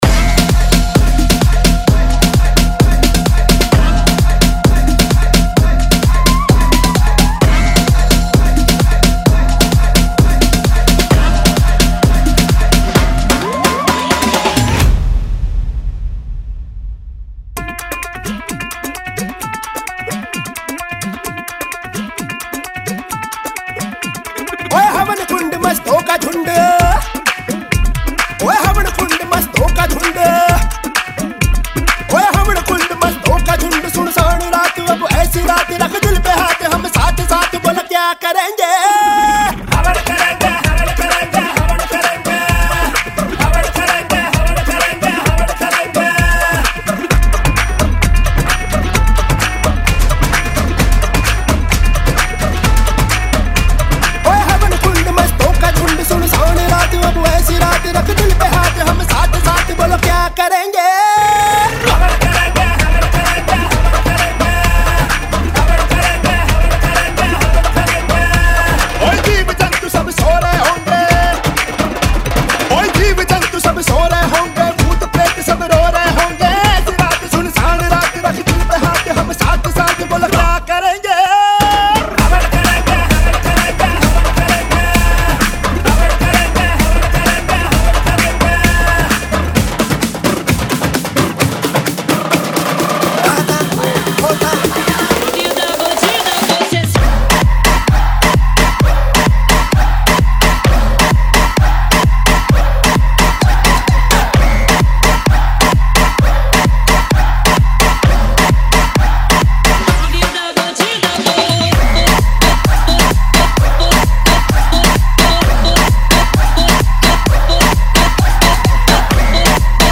EDM Remix | Dance Remix Song
Dholki Remix Mp3 Song Free
Category: Latest Dj Remix Song